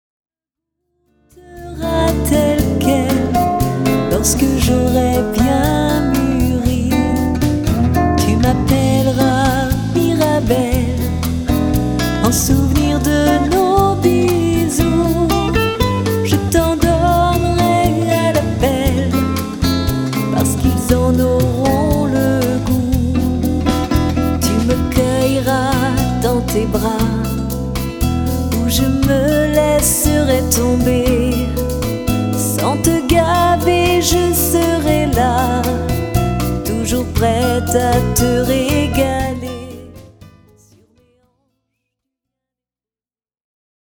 Une voix qui enchante...